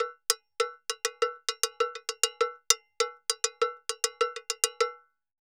Campana_Salsa 100_2.wav